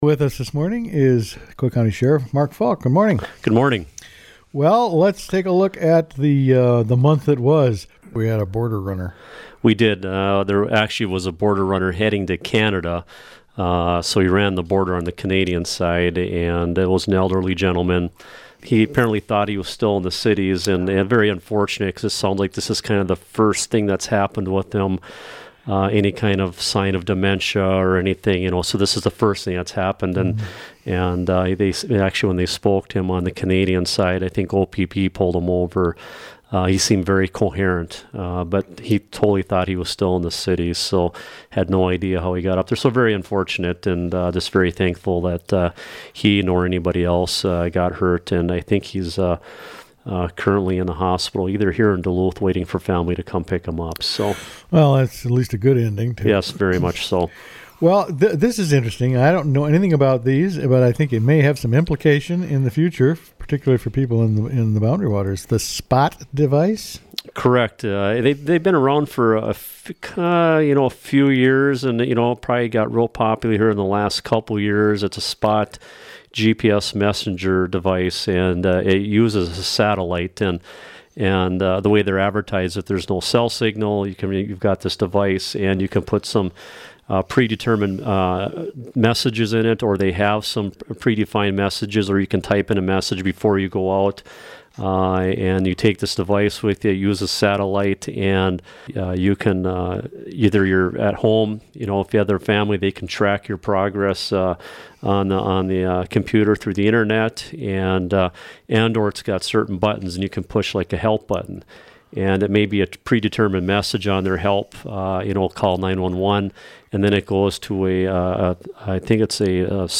Sheriff talks about GPS Boundary Waters rescue